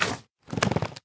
minecraft / sounds / mob / magmacube / jump3.ogg
jump3.ogg